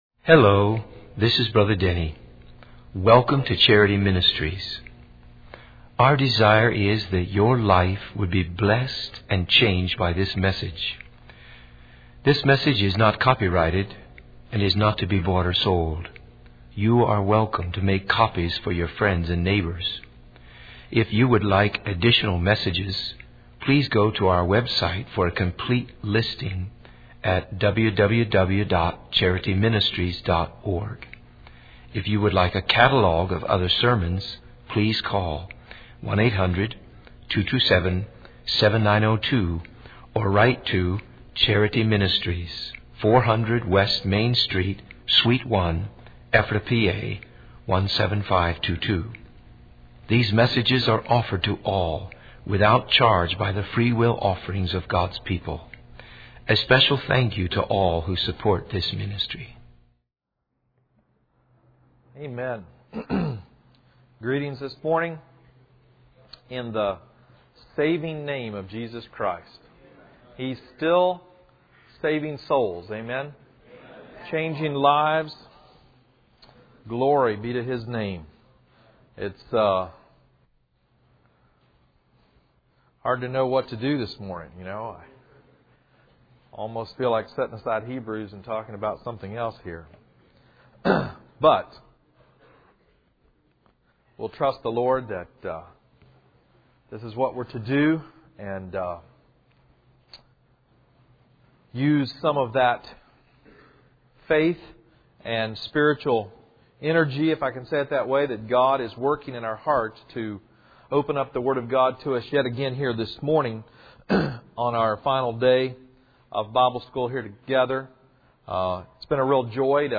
In this sermon, the preacher emphasizes the importance of discerning between good and evil teachings in Christian circles. He refers to Hebrews and Romans to explain that believers need to move from being on 'milk' to 'meat' in their understanding of God's righteousness.